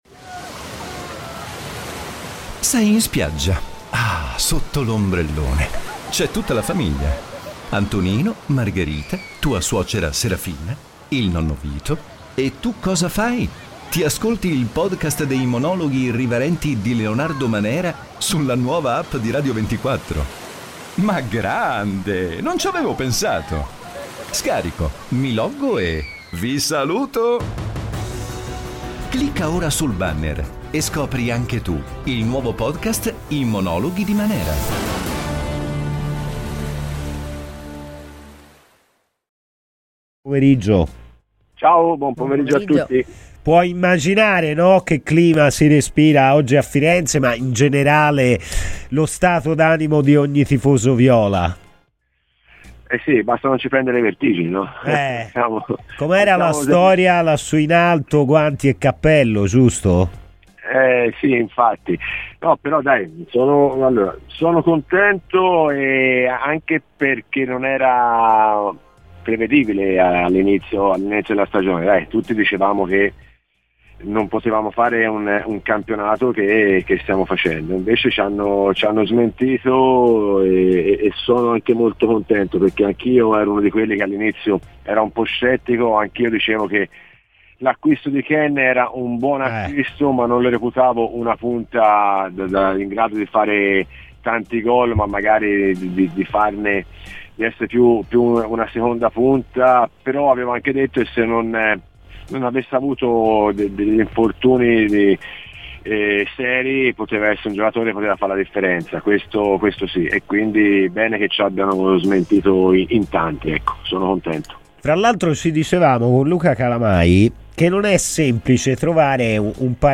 ASCOLTA IL PODCAST PER L'INTERVISTA COMPLETA Daniele Carnasciali a Radio FirenzeViola